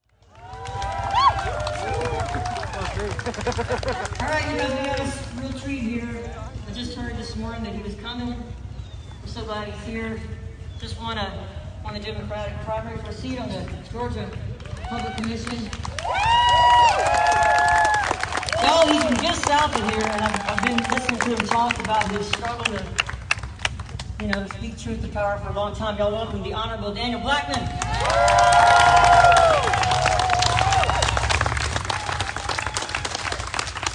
lifeblood: bootlegs: 2020-06-14: peace and unity rally at hancock park - dahlonega, georgia (amy ray)
(captured from a facebook livestream)